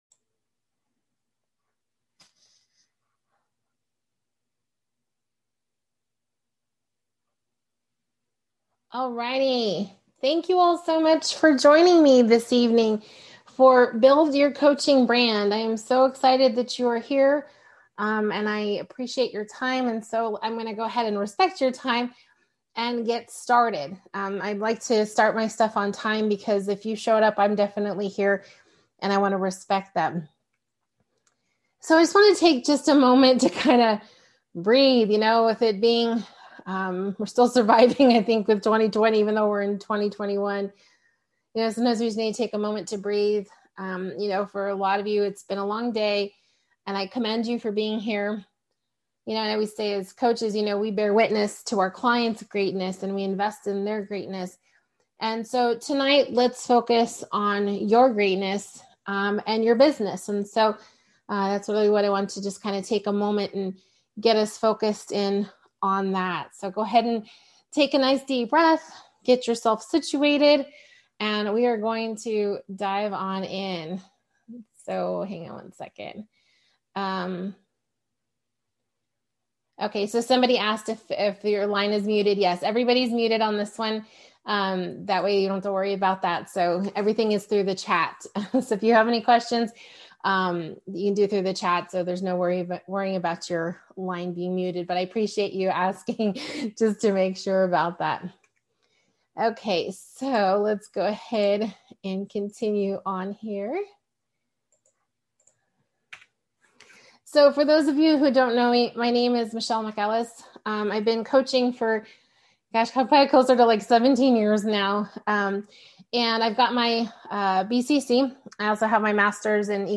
Join us for a complimentary masterclass where you will learn: